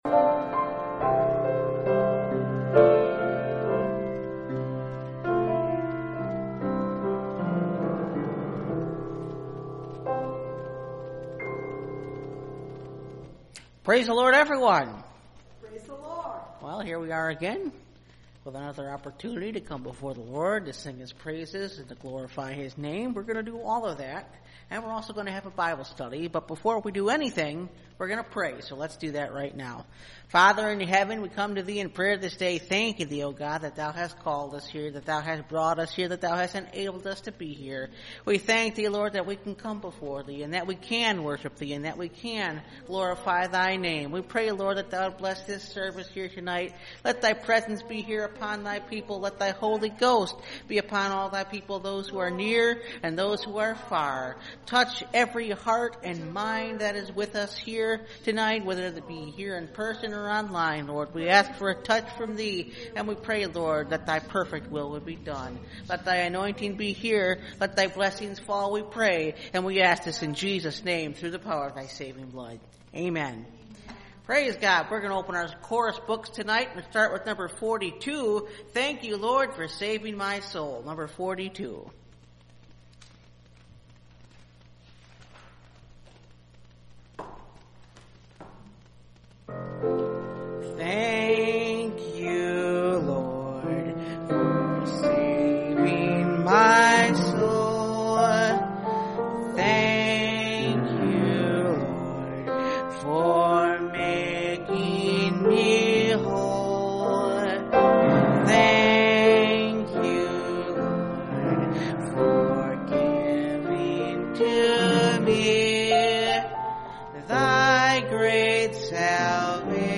And They Overcame Him – Part 1 – Last Trumpet Ministries – Truth Tabernacle – Sermon Library